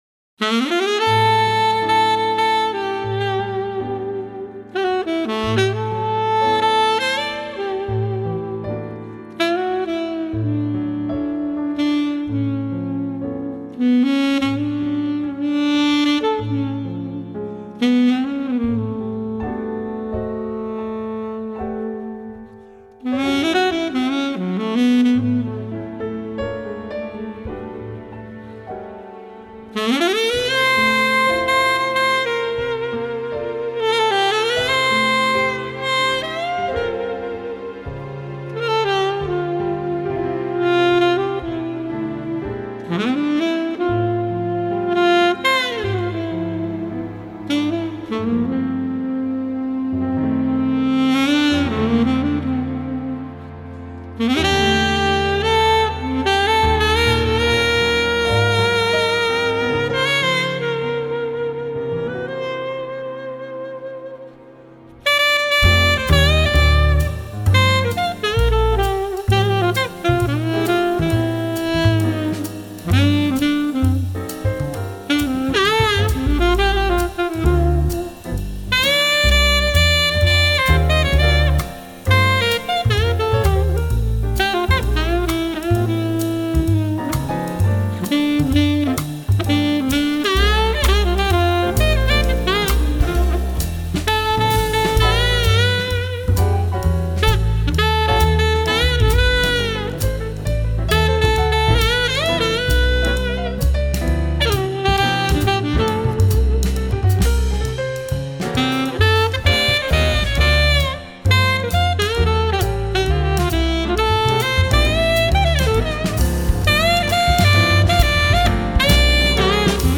风格：Smooth Jazz
精品的smooth jazz